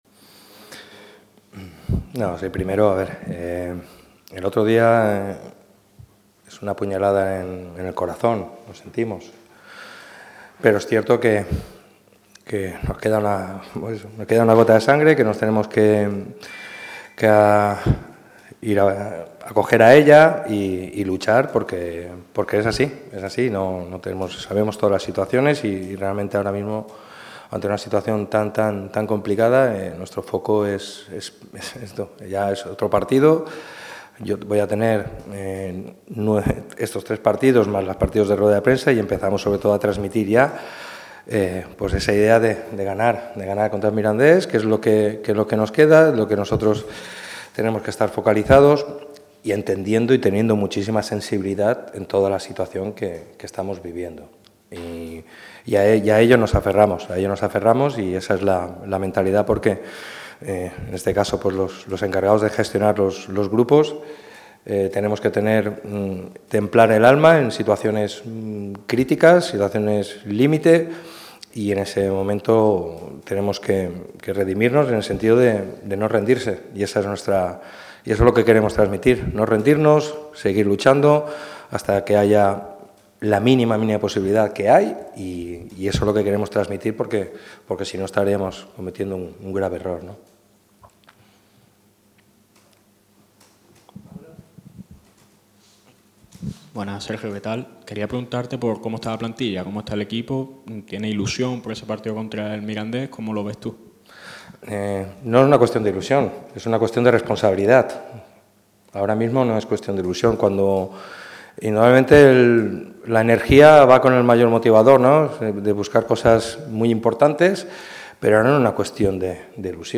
El entrenador del Málaga CF ha comparecido este mediodía en la sala de prensa de La Rosaleda en la previa del choque ante el Mirandés este sábado a las 21:00 horas.